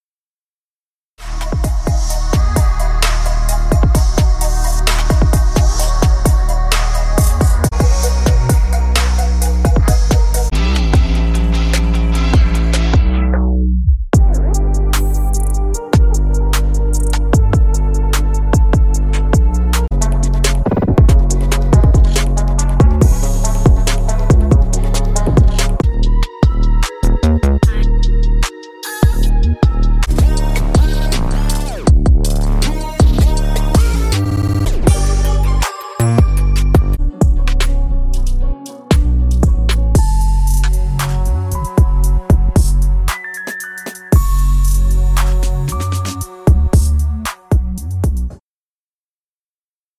- 31 个 808s 和低音循环
- 节奏/BPM 115-160